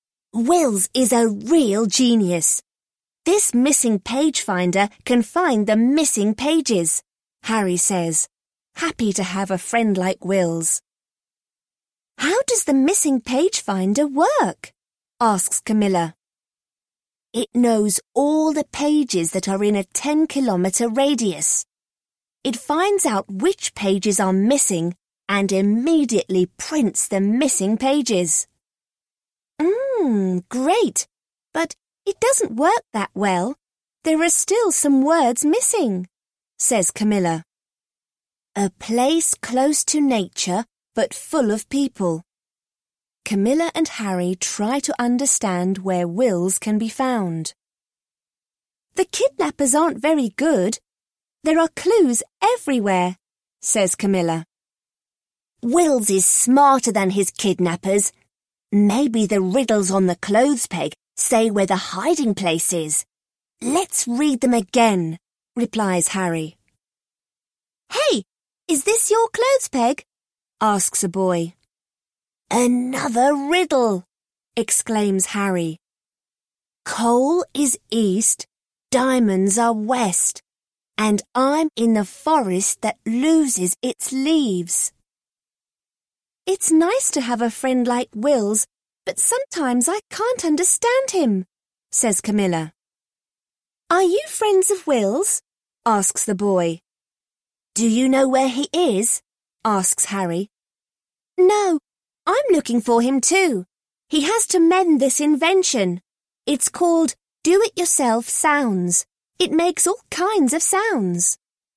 In Search of a Missing Friend (EN) audiokniha
Ukázka z knihy